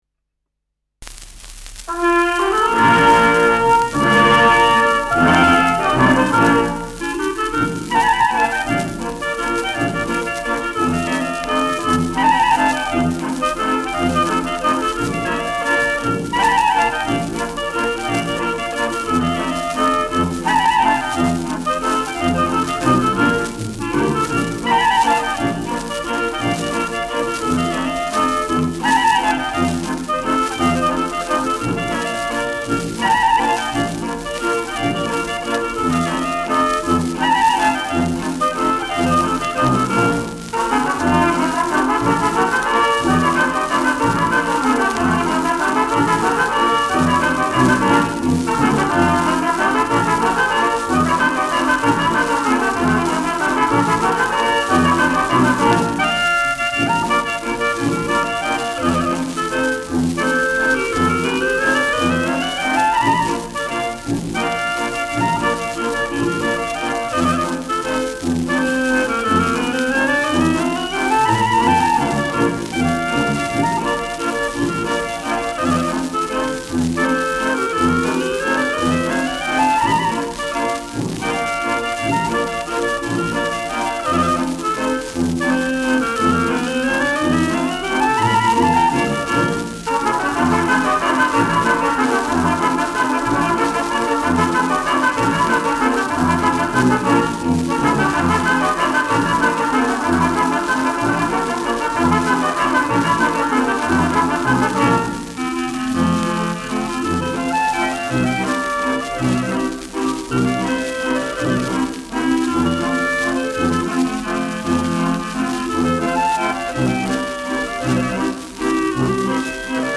Schellackplatte
präsentes Knistern : ab 2’00’’ Nadelgeräusch
Dachauer Bauernkapelle (Interpretation)
[München] (Aufnahmeort)